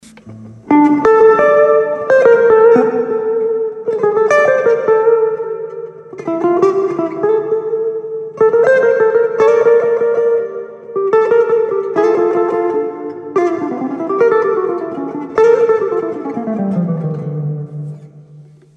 Which pickup sound do you think is better and more beautiful for gypsy lead guitar?
Peche+AER compact60+60%reverb effect